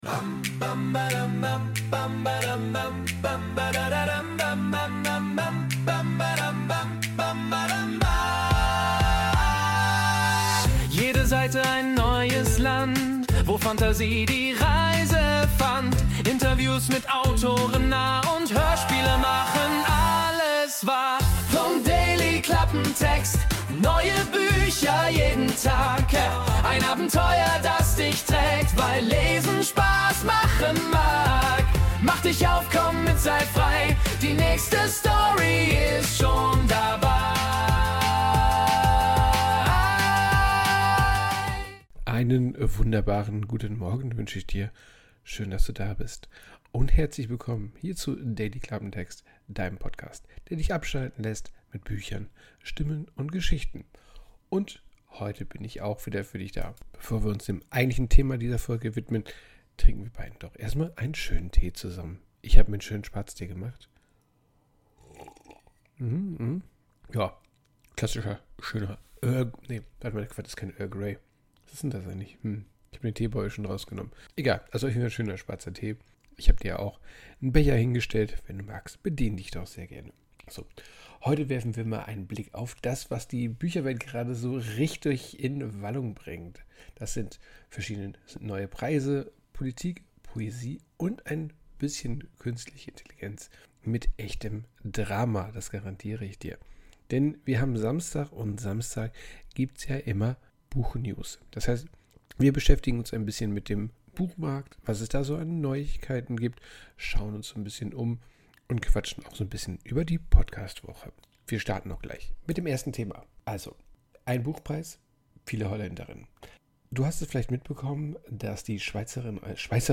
Intromusik: Wurde mit der AI Music erstellt.